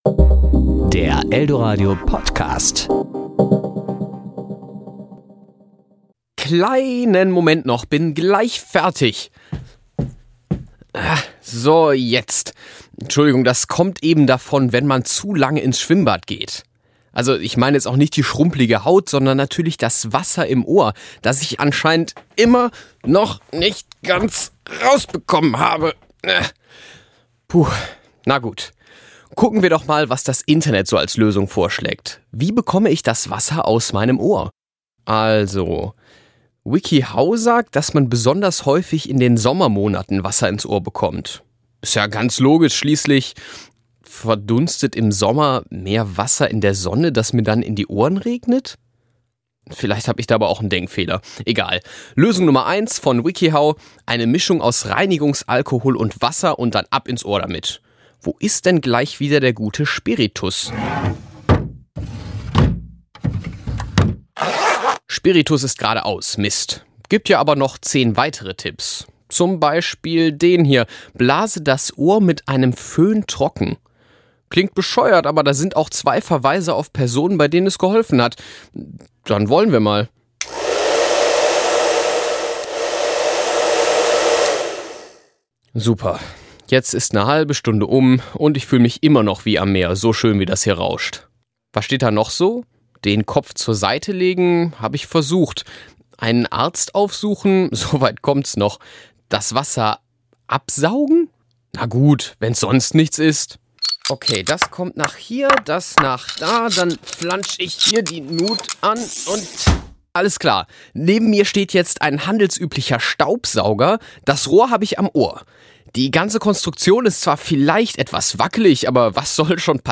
Serie: Interview